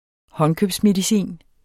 Udtale [ ˈhʌnkøbsmediˌsiˀn ]